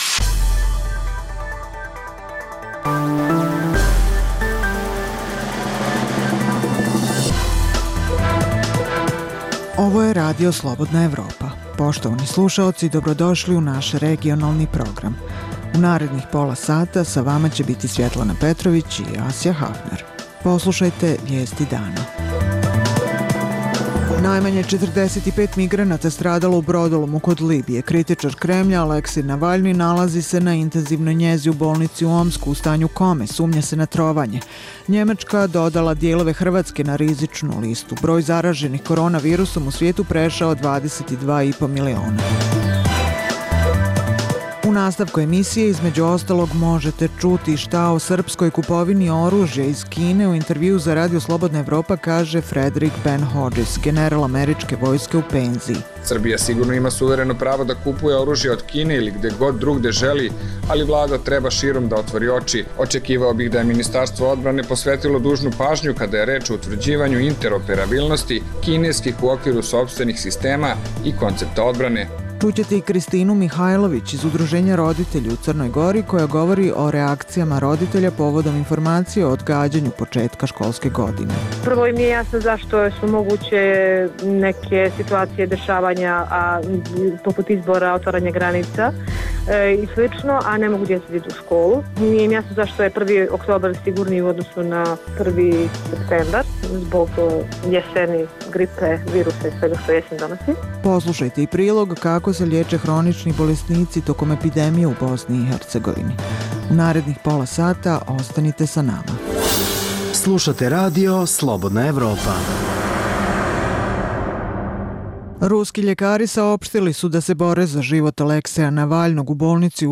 Iz emisije: Šta o srpskoj kupovini oružja od Kine u intervjuu za RSE kaže Frederik Ben Hodžis, general američke vojske u penziji. Kakve su reakcije roditelja u Crnoj Gori povodom informacije o odgađanju početka školske godine.